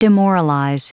Prononciation du mot demoralize en anglais (fichier audio)